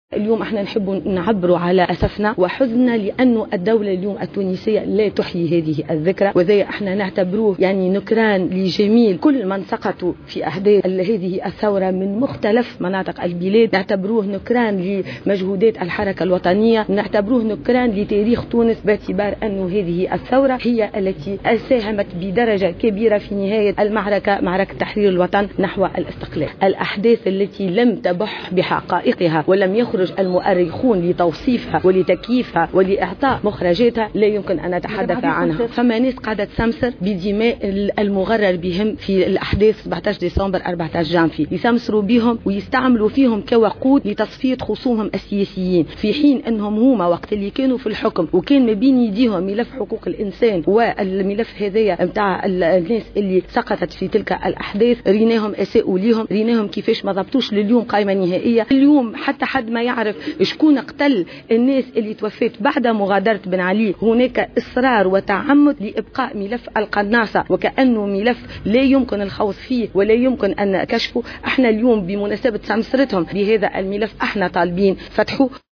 واعتبرت موسي، في تصريح لمراسل الجوهرة أف أم، على هامش إحياء، الذكرى 68 لثورة 18 جانفي 1952 ، اليوم السبت في بنزرت، أنه قد تمت التغطية على حقيقة ما حصل في تلك الفترة، في محاولة لتوظيف هذا الملف سياسيا، وتغطية الحقائق من خلال اعتماد عدة آليات من بينها التستر على حيثيات الملف، واستغلال هيئة الحقيقة والكرامة لإعادة المحاكمات بما يرضي الأطراف التي تقف وراءها.